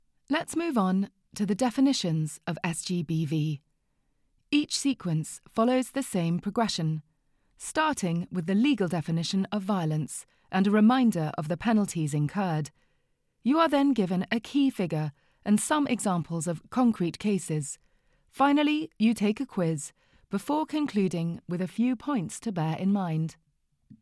Inglés (Británico)
Comercial, Natural, Versátil, Profundo, Cálida
E-learning